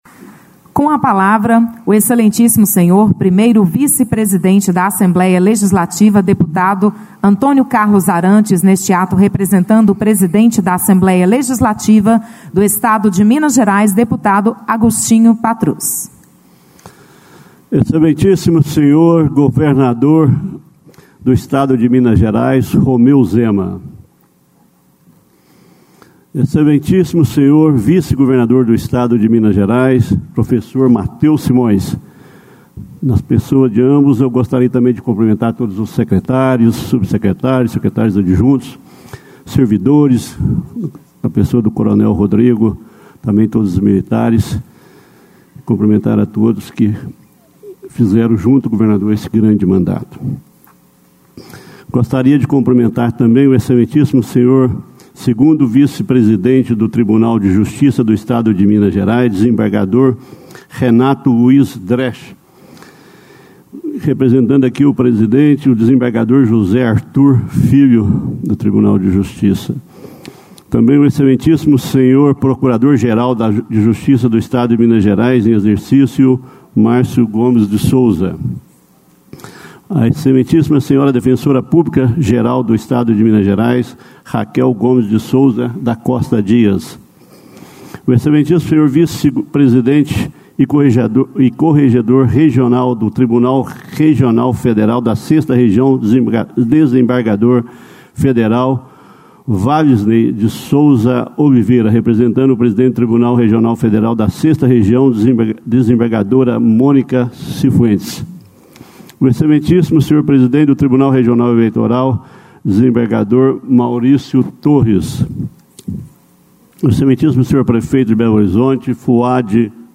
Discursos e Palestras
O vice-presidente da ALMG presidiu posse de Romeu Zema no novo mandato, destacando em seu pronunciamento a aprovação de leis para enfrentamento à Covid-19, crise fiscal e acordo da Vale.